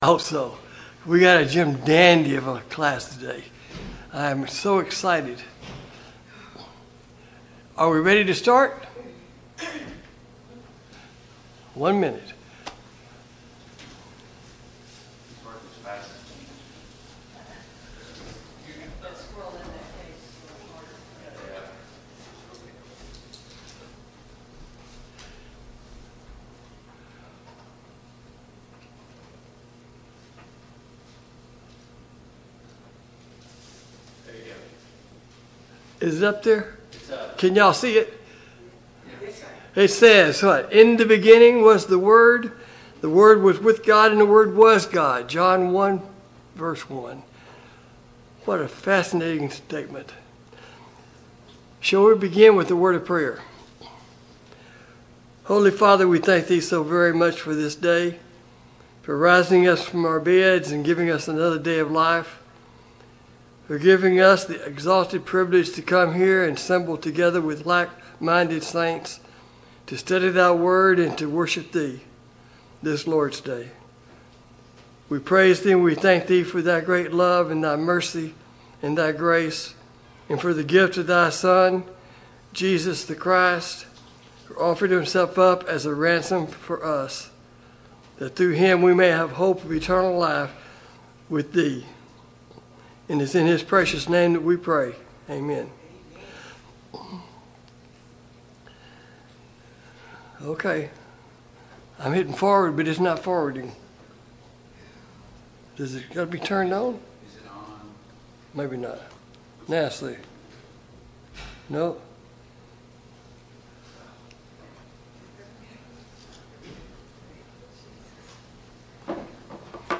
Passage: Mark 5 Service Type: Sunday Morning Bible Class